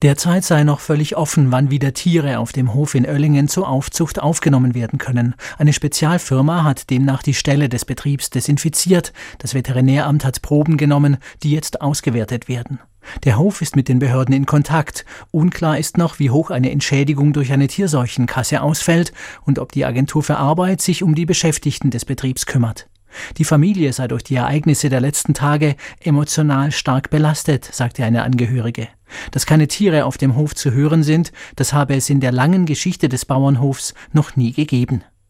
Mitglied des Familienbetriebs aus Öllingen
"Wir können es noch nicht fassen", sagt die Frau mit stockender Stimme ins Telefon.